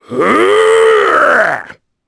Kaulah-Vox_Casting4_jp.wav